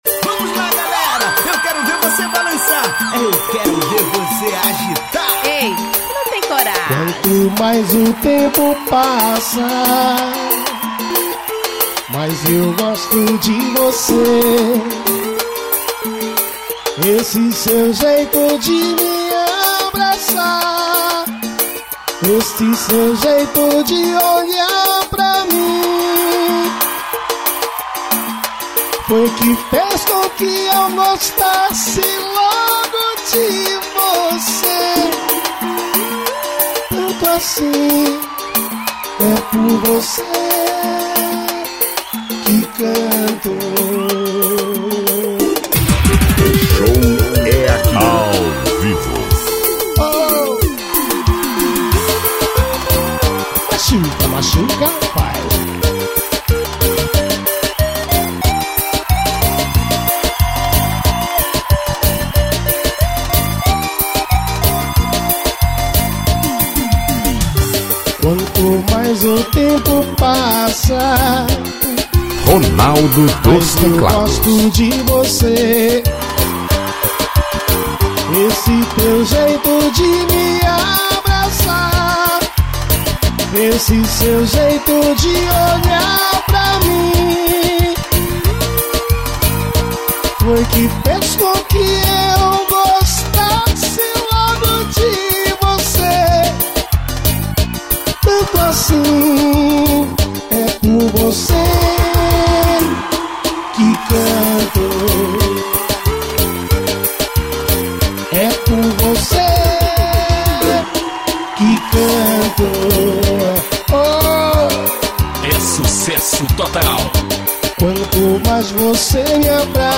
AO VIVO NO BAR TUTU.